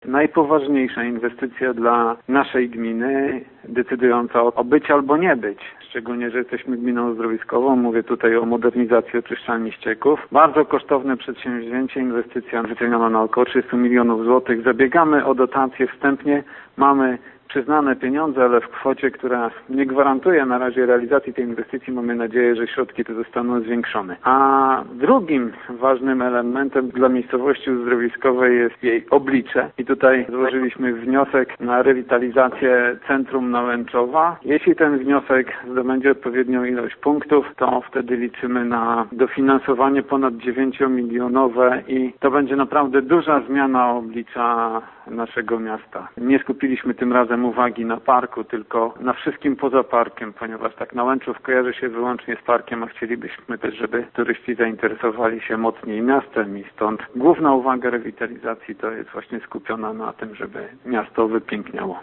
Do najważniejszych zadań, które staną przed nowymi władzami Nałęczowa burmistrz Ćwiek zalicza: modernizację oczyszczalni ścieków i rewitalizację centrum miasta: